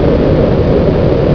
Engine2
ENGINE2.WAV